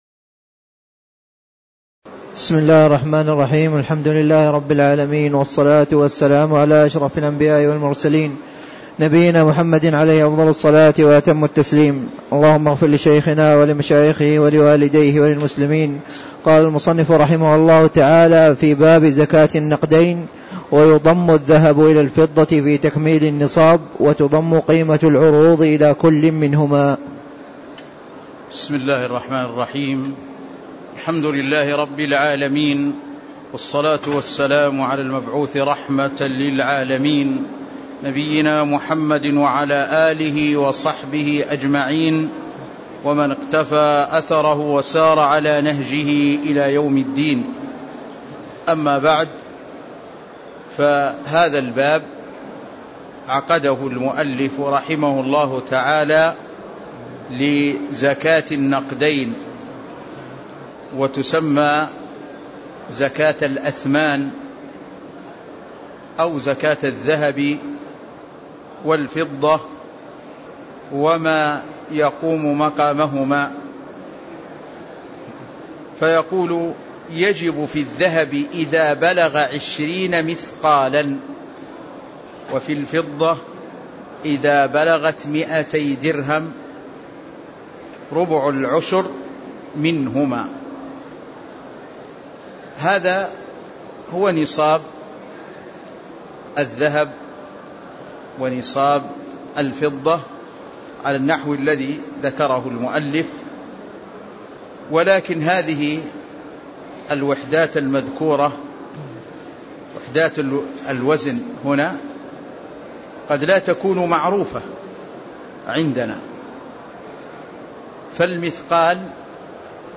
تاريخ النشر ١٩ ربيع الثاني ١٤٣٦ هـ المكان: المسجد النبوي الشيخ